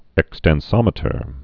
(ĕkstĕn-sŏmĭ-tər)